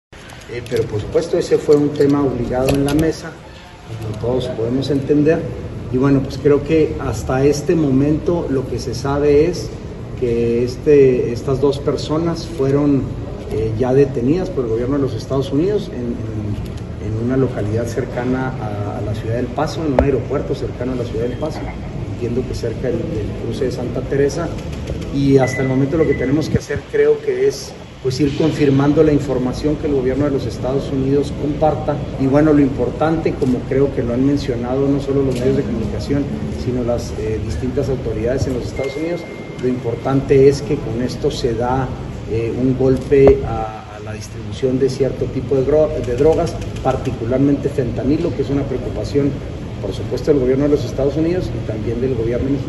AUDIO: CÉSAR JÁUREGUI MORENO, FISCAL GENERAL DEL ESTADO (FGE)